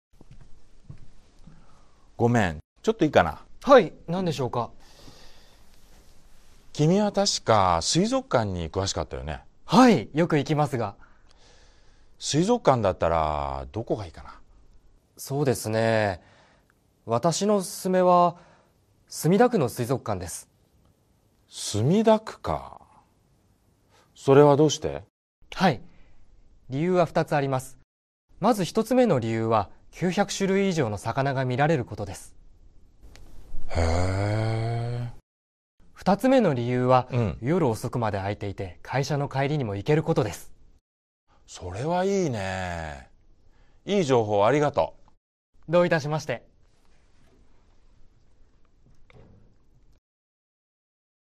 Role-play Setup